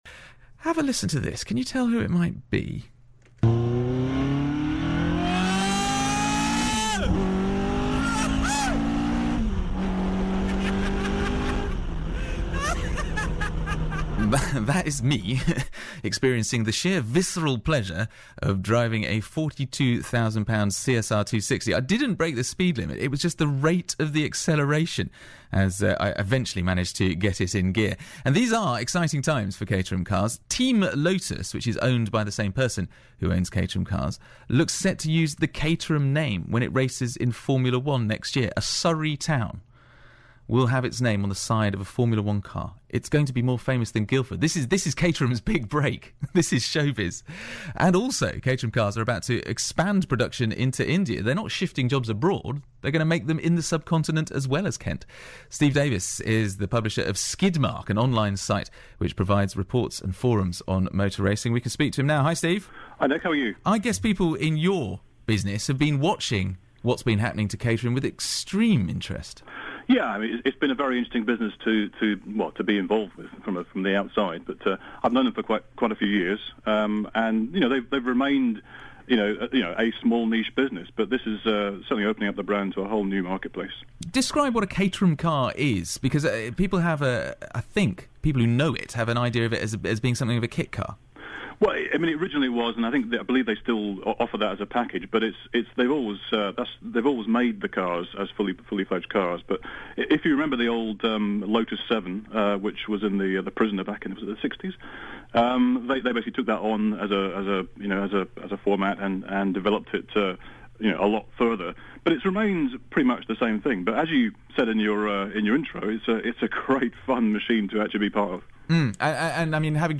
bbc-surrey-caterham2.mp3